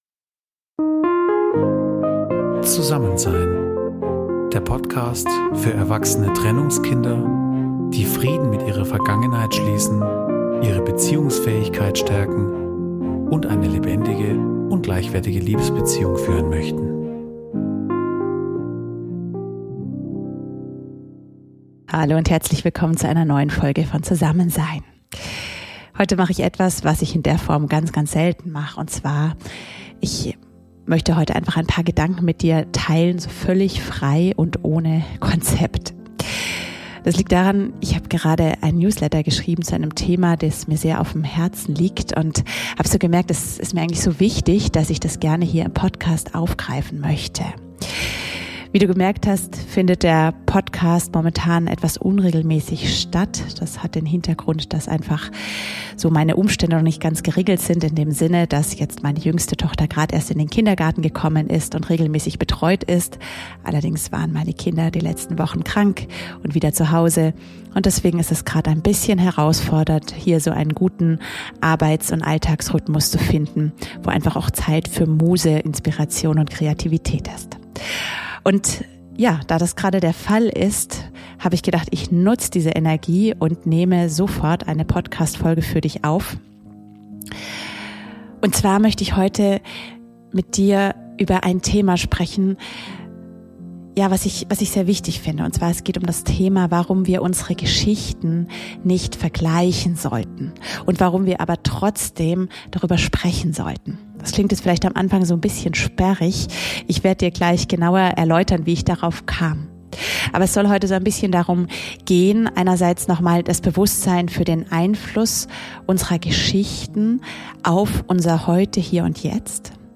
Und weil mich das so bewegt hat, habe ich etwas getan, was ich in dieser Form noch nie gemacht habe: Ich habe ganz spontan eine Podcastfolge für dich aufgenommene, ohne Konzept und Skript, sondern einfach mit den Gedanken, die mir aus der Seele geflossen sind.
Heute ist nichts geschnitten, sondern einfach alles ganz ungefiltert direkt von mir an dich!